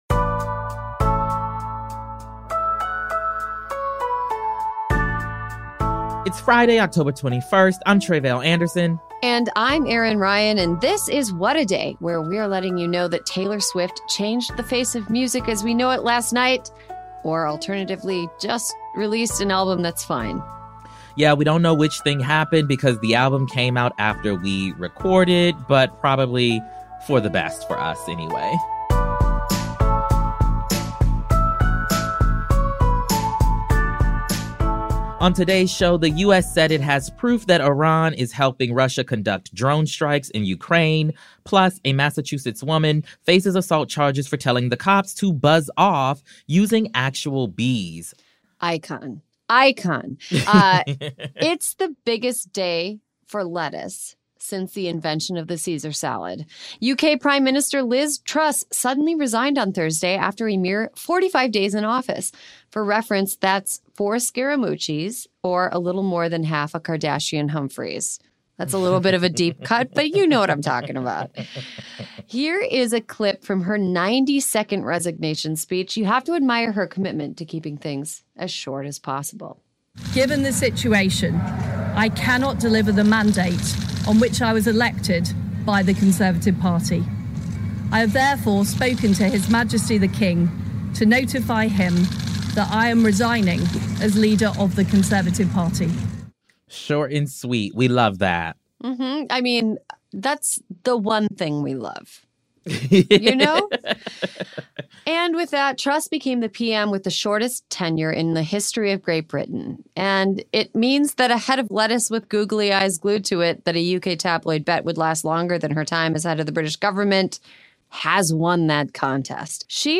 Oklahoma House Representative Mauree Turner — the first out non-binary state lawmaker in the country and the first Muslim member of the Oklahoma Legislature — tells us how they’ve used their position to fight for their constituents and their communities.